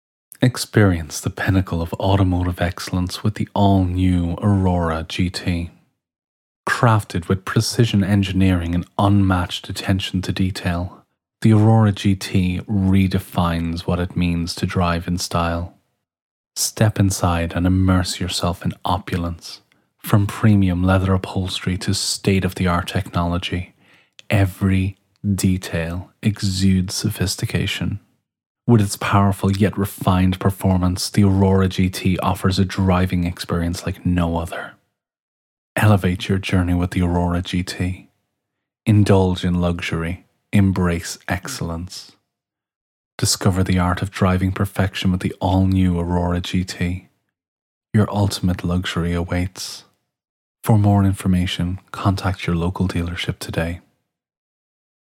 Every file is recorded to broadcast quality and delivered ready to use — no fuss.
Car Ad Demo